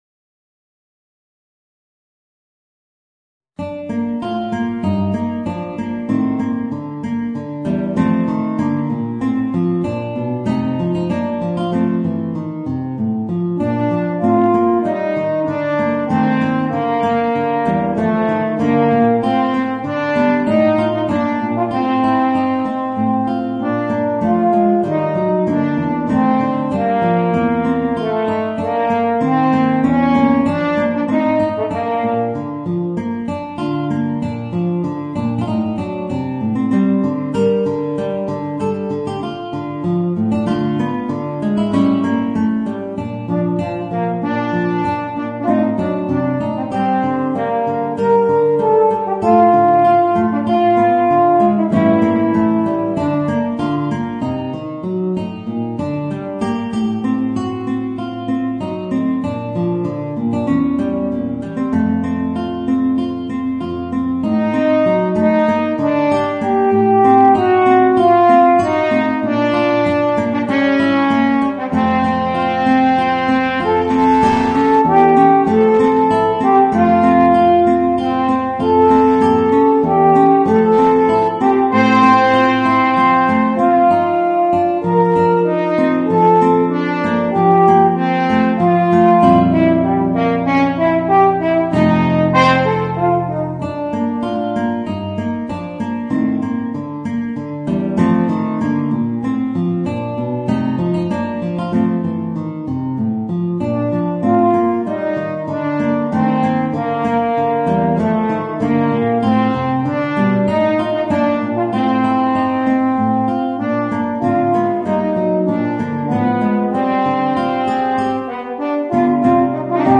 Voicing: Guitar and Horn